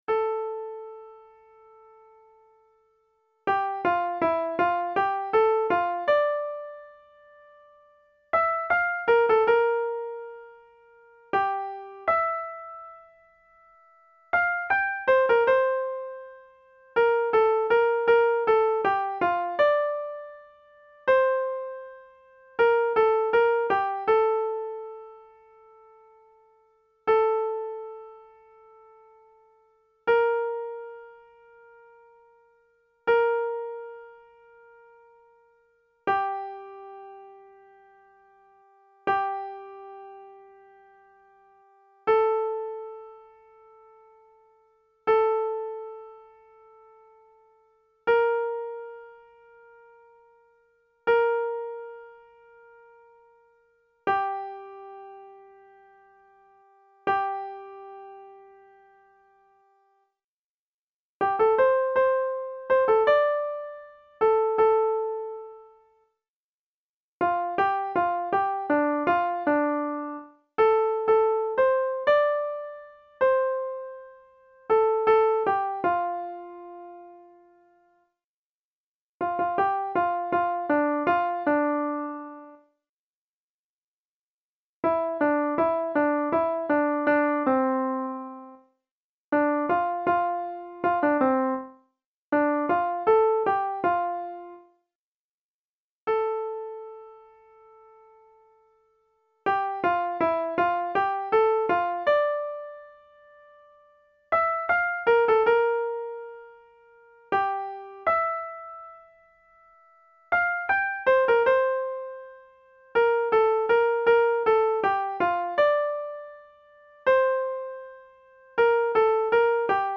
1.Sopran:
A-whiter-shade-of-palesopran1.mp3